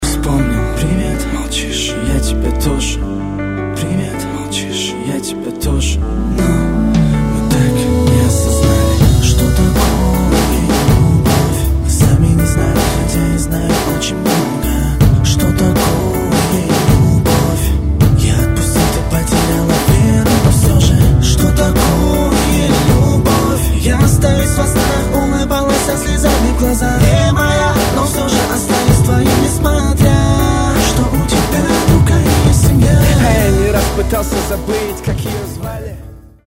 304 Категория: Rap, RnB, Hip-Hop Загрузил